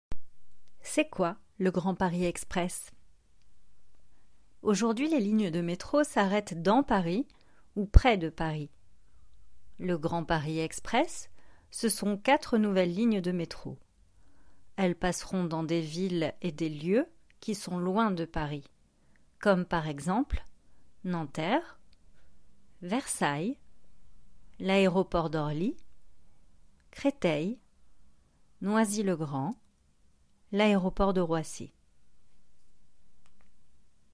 Le Grand Paris Express (pour méthode FALC / Français facile à comprendre)
Voix off
16 - 40 ans - Mezzo-soprano